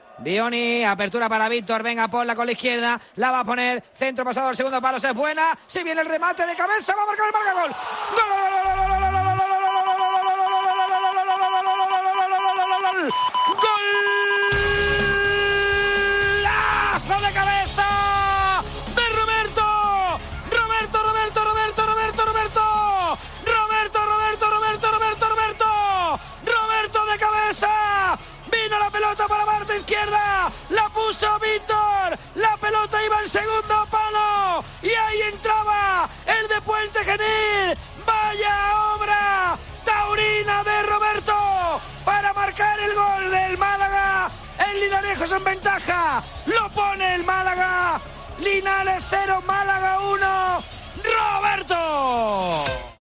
(Audio) Así sonó el gol del triunfo de Roberto en COPE MÁS Málaga